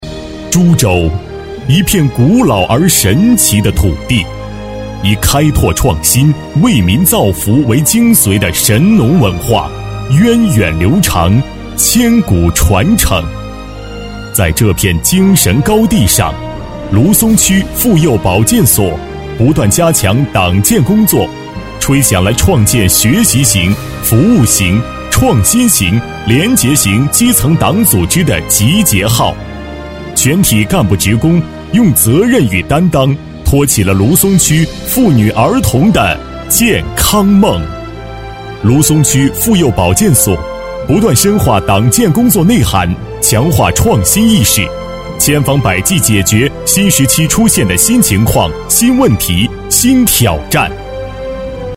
大气震撼 企业专题,人物专题,医疗专题,学校专题,产品解说,警示教育,规划总结配音
大气震撼男中音，激情厚重、磁性、悠扬大气。